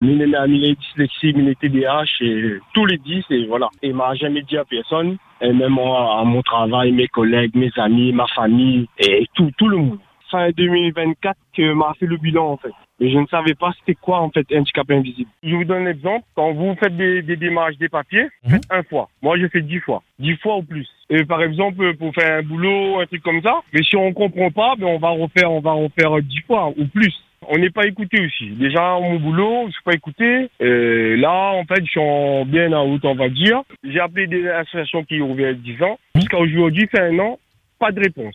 Un jeune homme a accepté de témoigner sur notre antenne. Il raconte un quotidien marqué par les difficultés scolaires, professionnelles et sociales, mais surtout par un profond sentiment d’incompréhension.